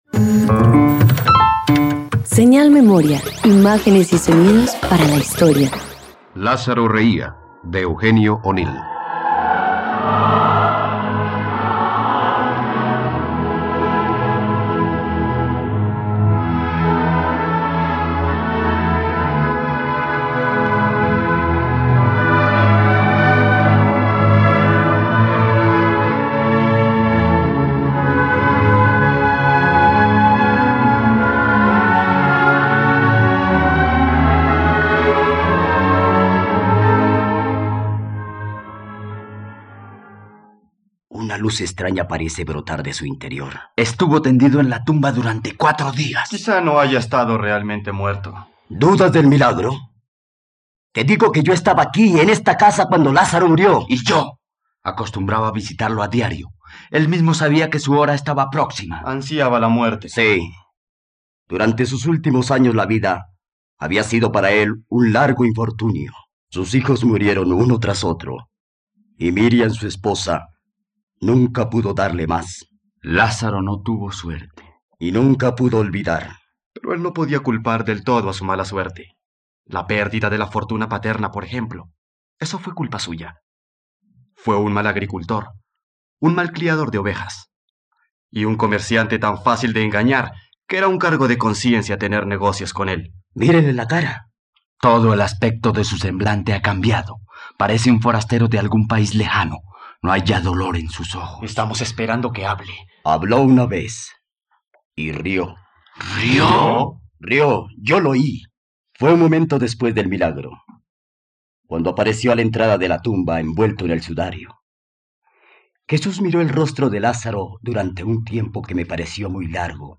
Lázaro reía - Radioteatro dominical | RTVCPlay